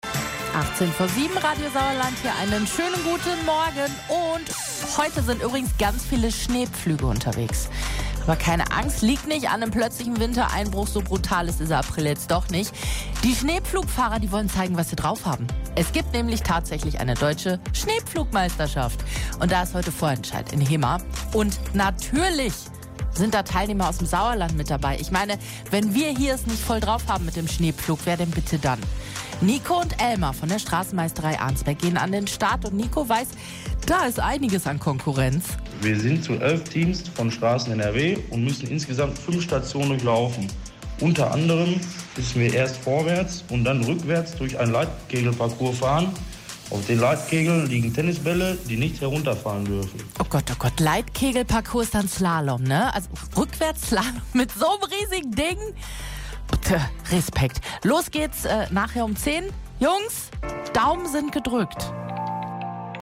Wir haben mit ihnen gesprochen.